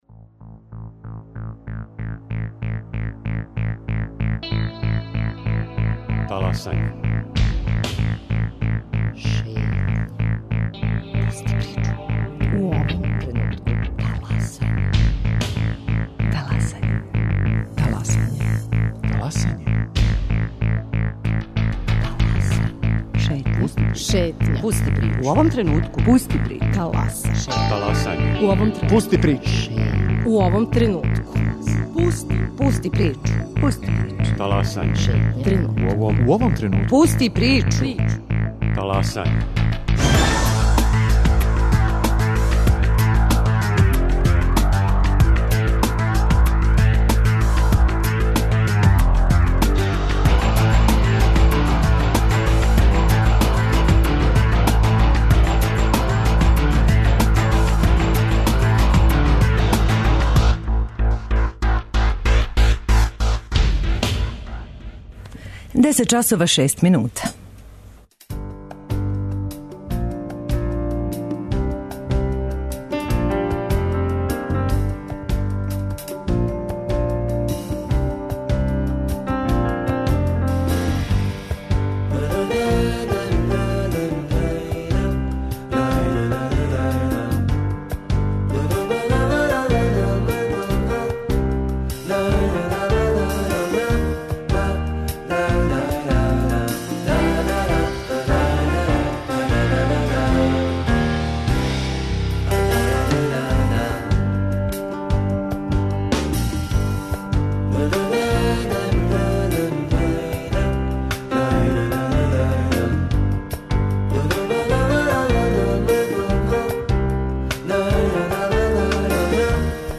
У госте нам долазе